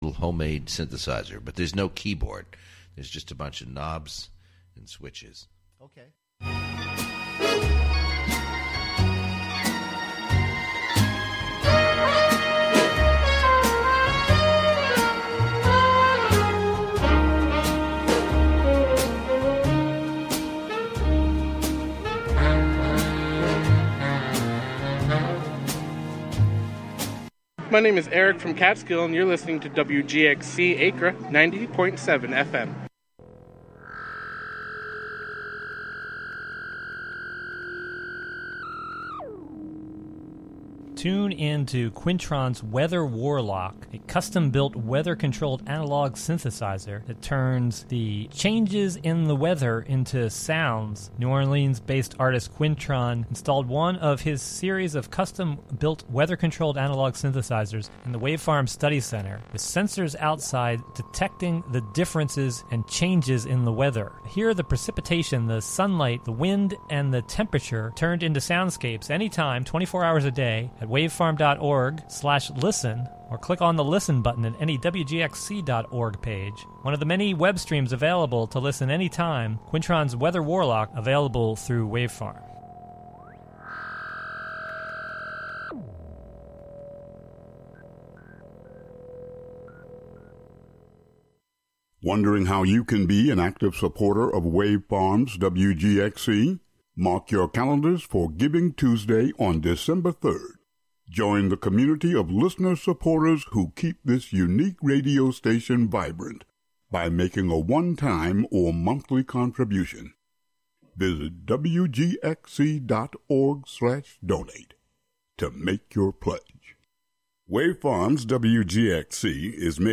Through Censored, The Word Shop, and Our Town Our Truth, we dig into the topics that matter. Our container: Radiolab, an open, experimental, youth-led programming and recording space. Get yr weekly dose of music appreciation, wordsmithing, and community journalism filtered through the minds and voices of the Youth Clubhouses of Columbia-Greene, broadcasting out of the Catskill Clubhouse, live on Fridays and rebroadcast Sunday at 7 a.m.